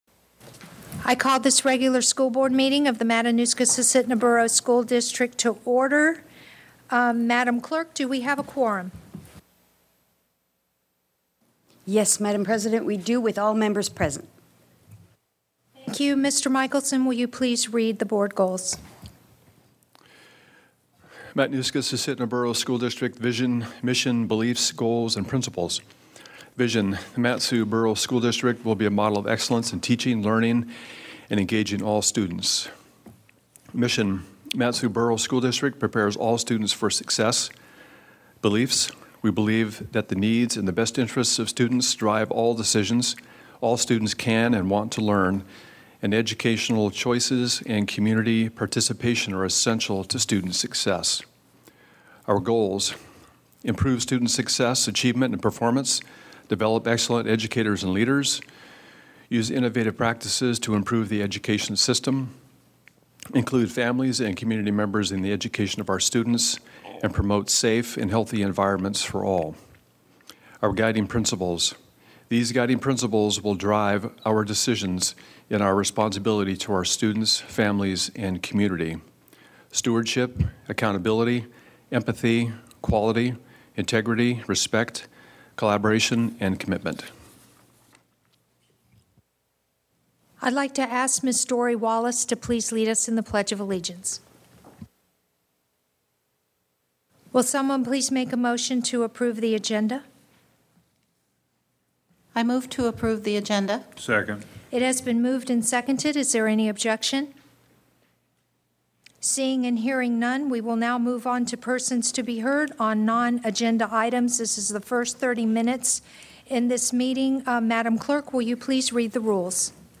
Sep 6, 2018 | School Board Meetings